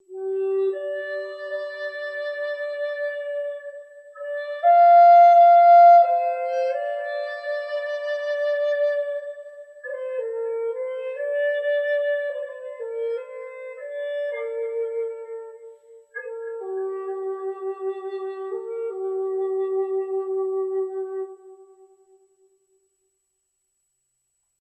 beam blue bright clear galaxy gleam glimmer glisten sound effect free sound royalty free Memes